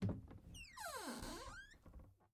Звуки шкафа
Звук відкриття дверцят шафки